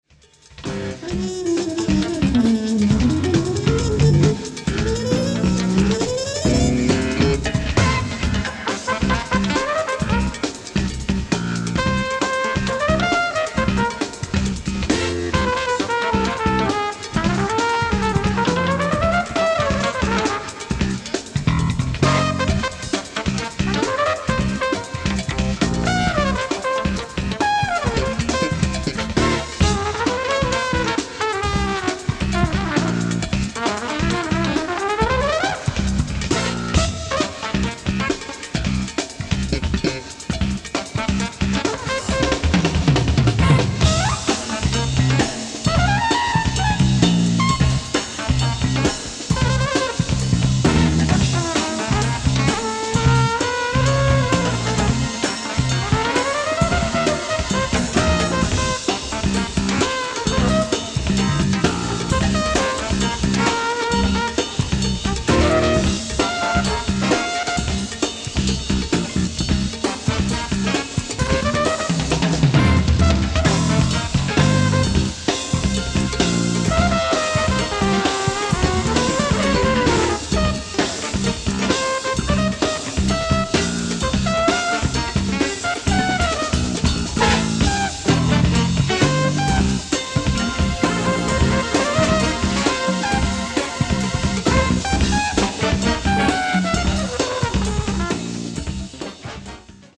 ライブ・アット・グランカバルガタ、ラス・パルマ、グラン・カナリア島、スペイン 11/10/1988
海外マニアによるレストア修正サウンドボード音源！！